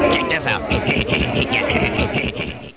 Digital Audio: 30K, 2.7 sec., 11,025 Hz sample rate, 8-bit sample resolution, mono